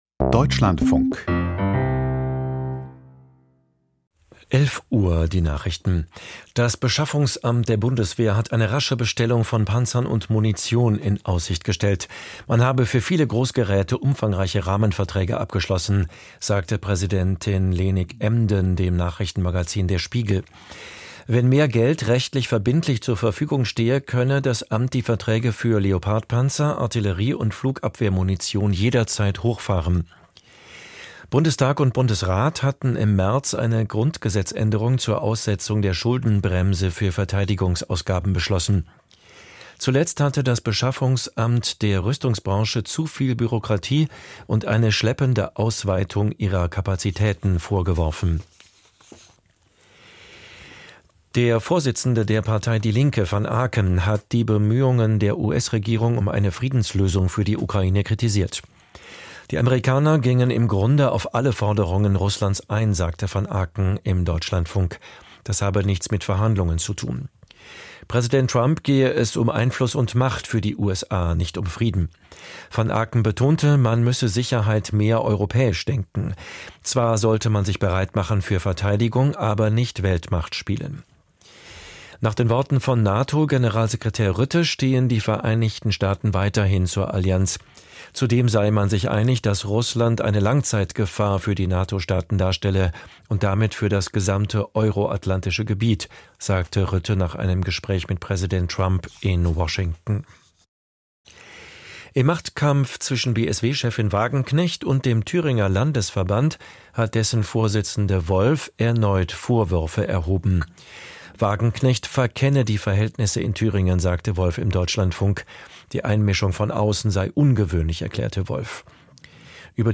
Die Deutschlandfunk-Nachrichten vom 25.04.2025, 11:00 Uhr